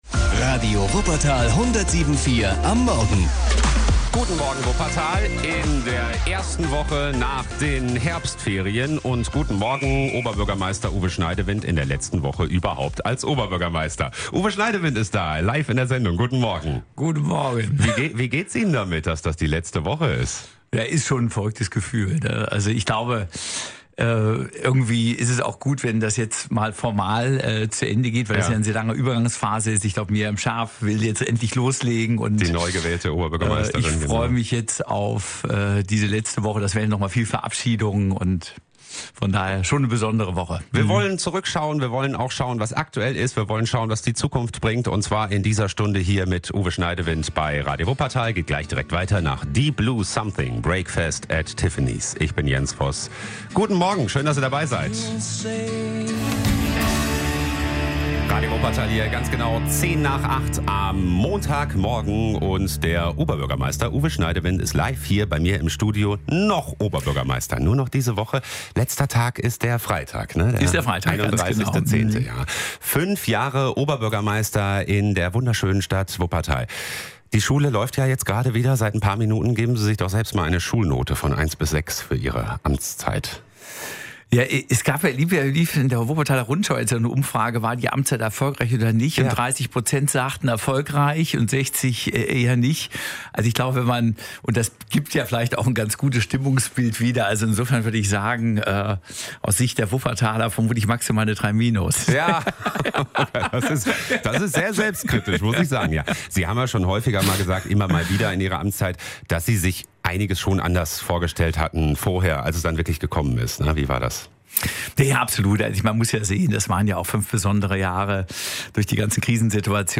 Oberbürgermeister Uwe Schneidewind im Abschieds-Interview
_-_interview_schneidewind_komplett.mp3